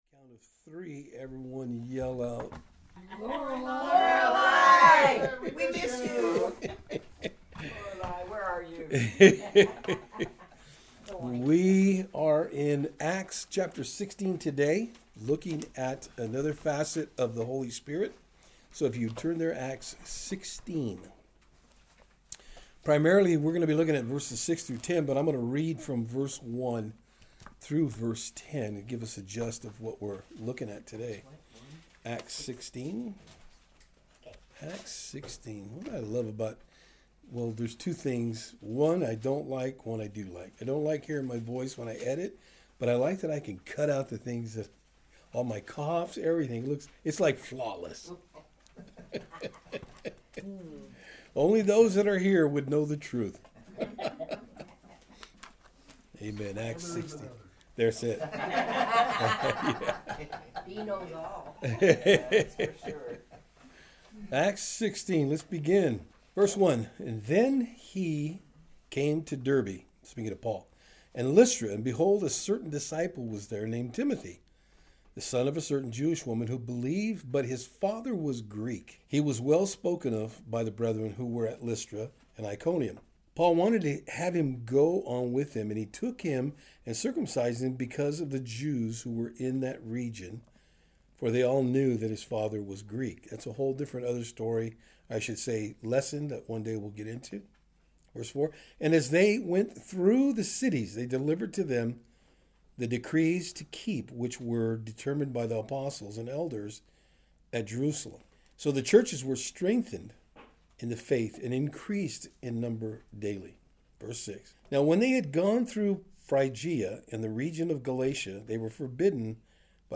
Acts 16 Service Type: Thursday Afternoon In our study today we will look at what exactly does it mean To Be Led By The Holy Spirit.